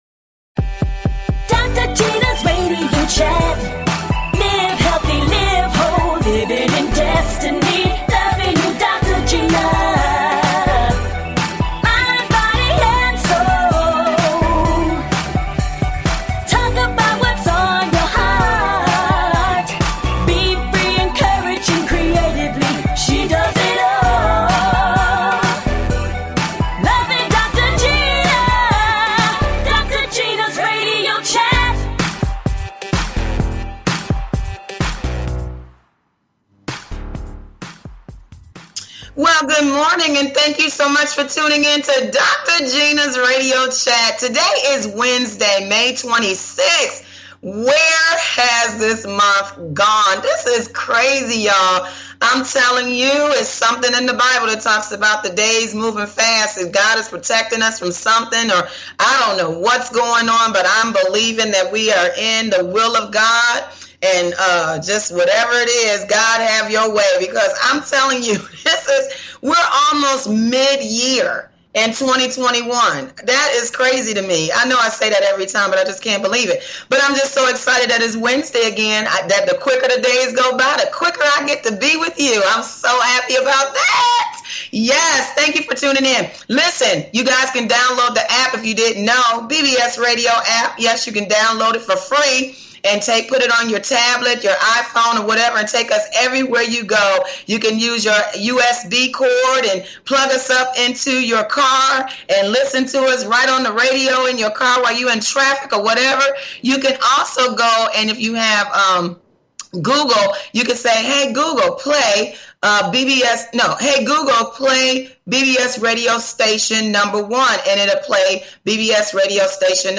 Talk Show Episode
A talk show of encouragement.